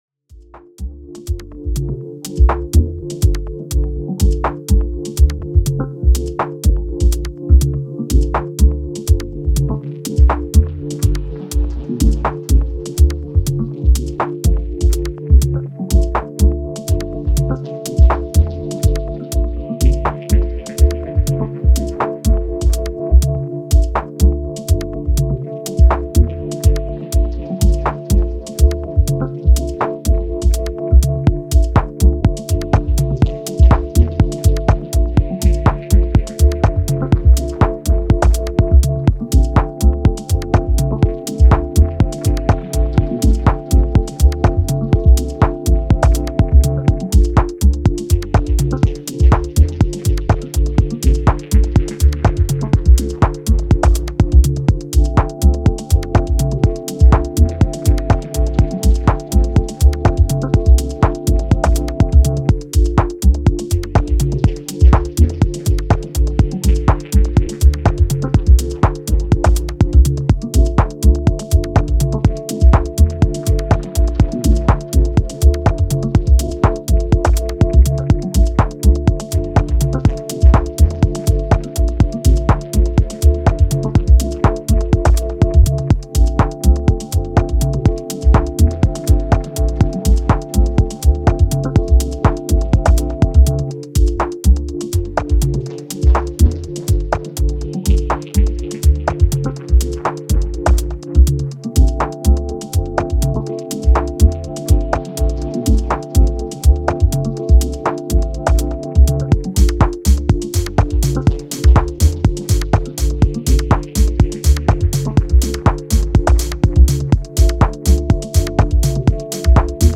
モダンでミニマルなハウス・グルーヴをストイックに追い求めていった、職人的な気質が光る一枚に仕上がっています！
フロアが集中力を持って踊ることが出来る機能を有したDJ ツールで、作り込まれた展開に高い計算を感じる仕上がり！！